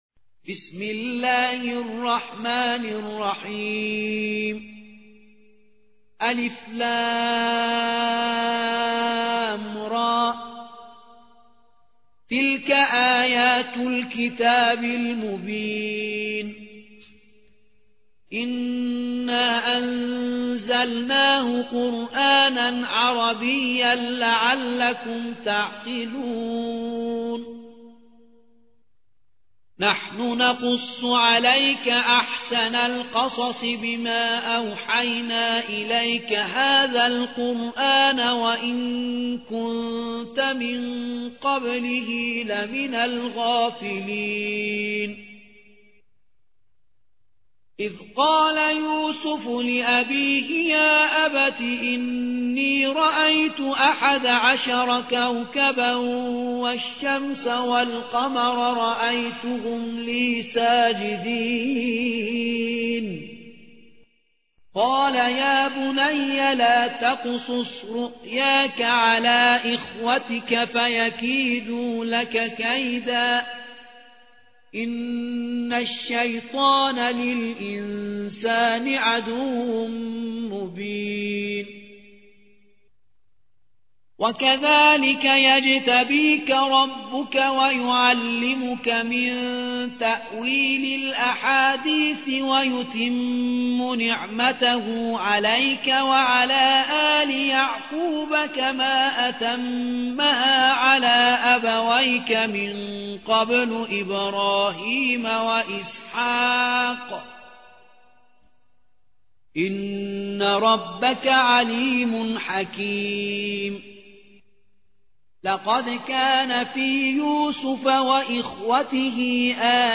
12. سورة يوسف / القارئ